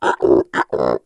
Звуки жирафа
Мультяшный звук с голосом жирафа